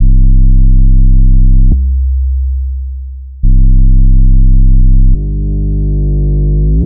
Index of /archive/TRUTH-HZ/2023_TRUTH-HZ_USB/Sample Packs/Crowdsourced Sample Pack/Drum Kit/808s & Subs